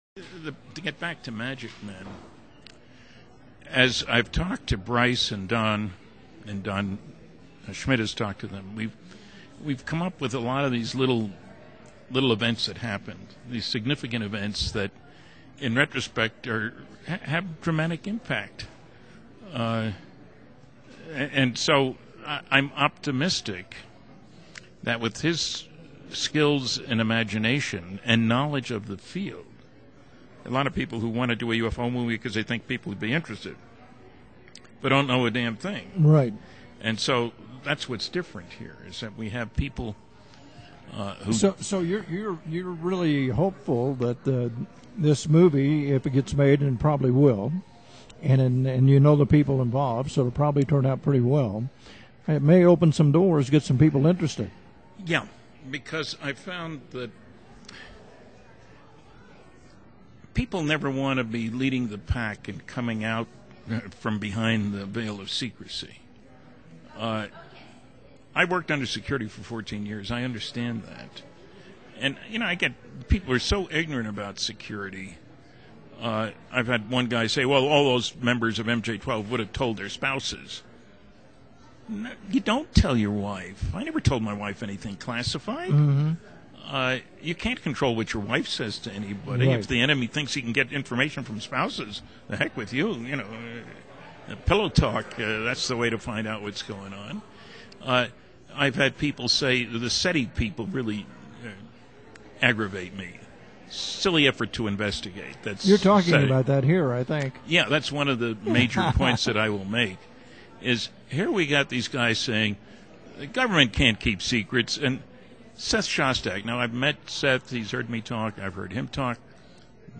Stanton Friedman - 2010: This program originates from the famed Angel Fire Resort in Northern New Mexico and was produced during the 2010 Paranormal Conference.